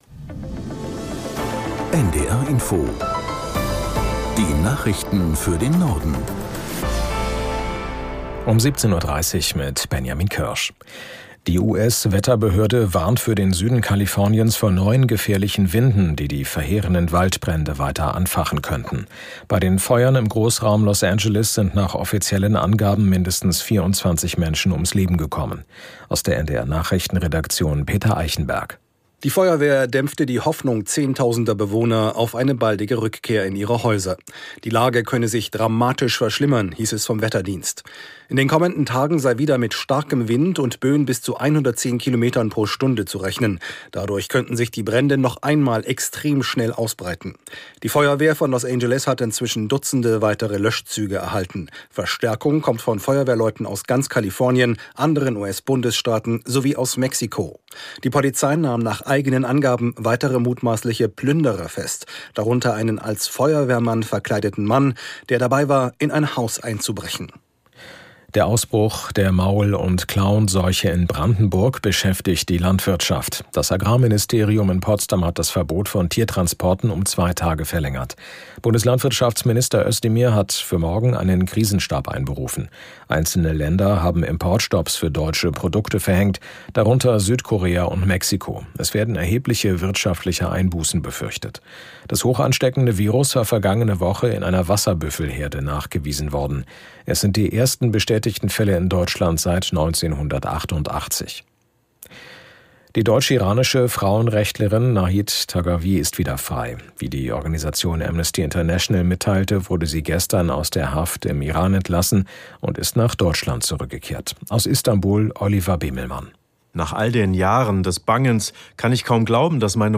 Nachrichten NDR Info Tägliche Nachrichten